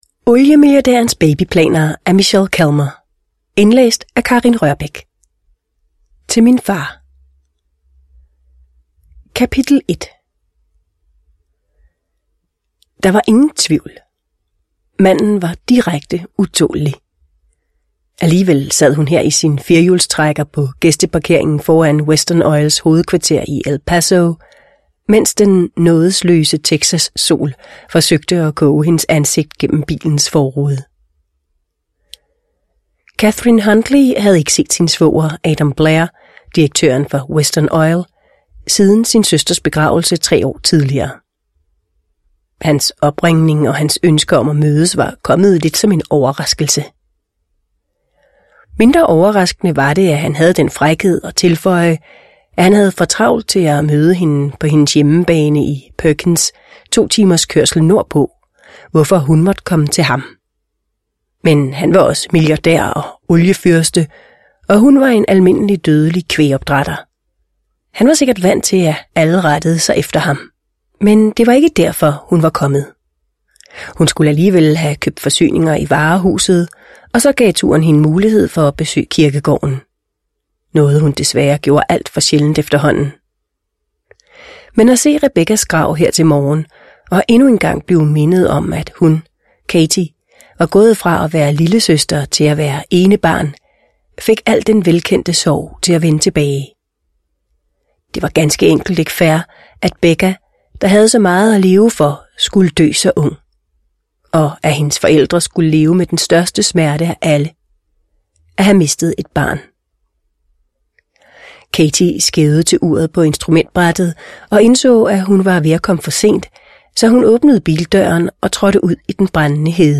Oliemilliardærens babyplaner – Ljudbok – Laddas ner